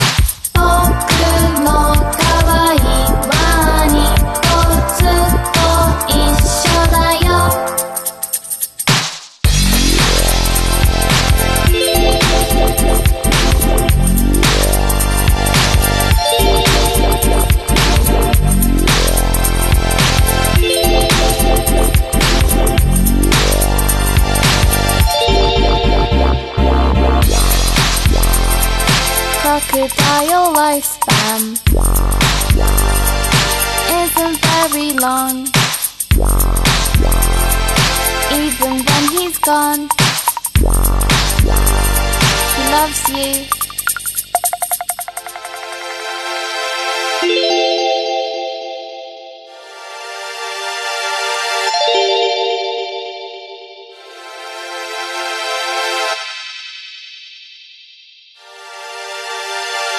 A wild stoat on the sound effects free download